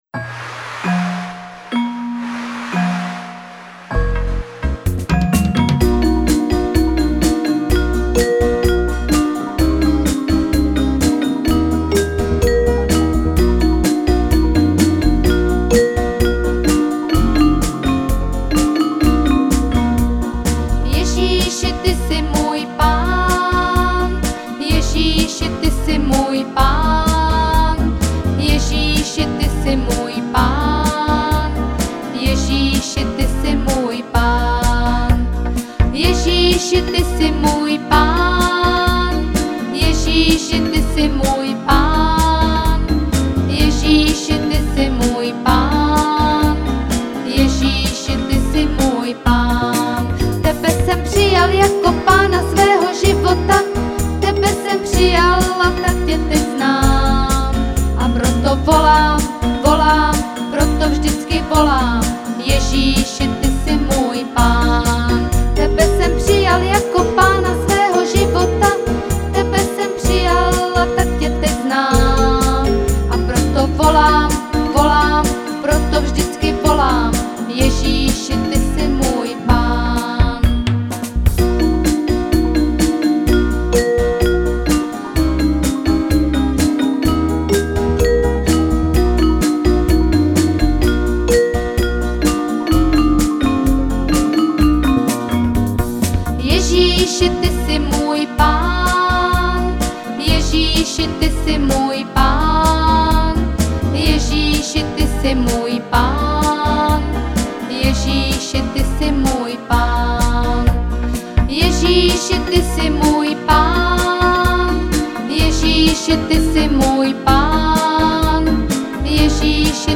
Křesťanské písně
Písničky pro děti